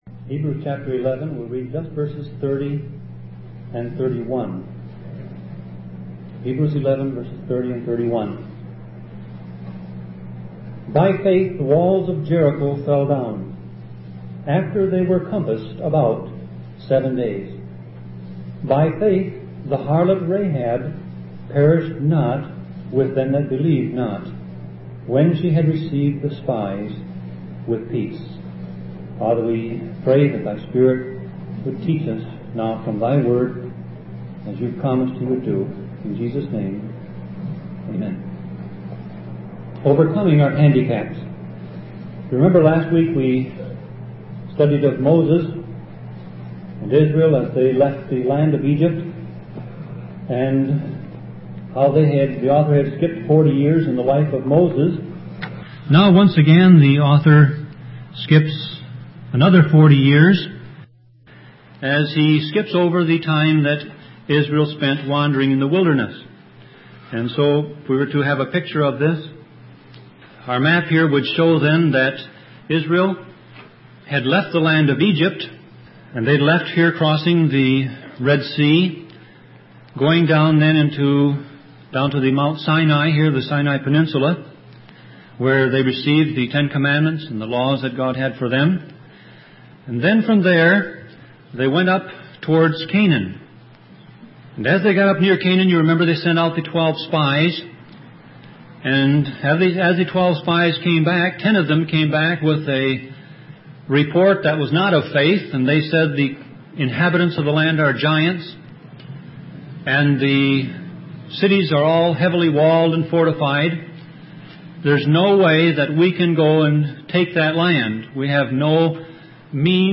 Sermon Audio Passage: Hebrews 11:30-31 Service Type